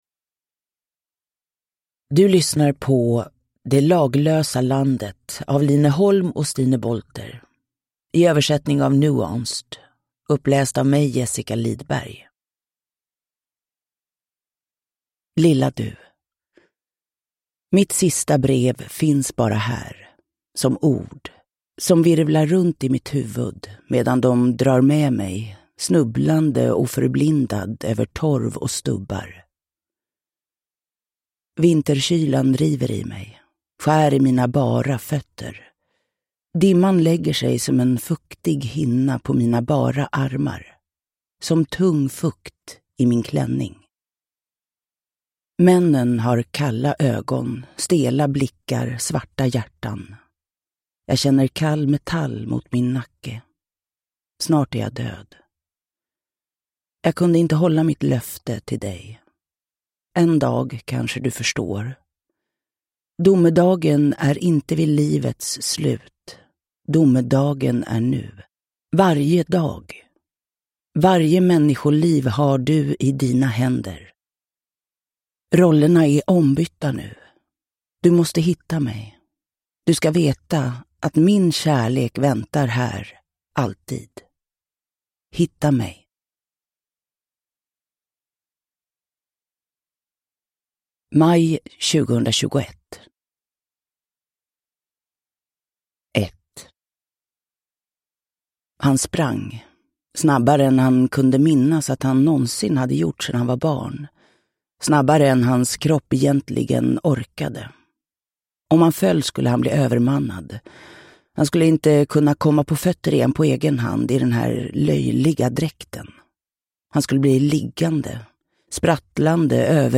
Det laglösa landet (ljudbok) av Stine Bolther | Bokon